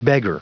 Prononciation du mot beggar en anglais (fichier audio)
Prononciation du mot : beggar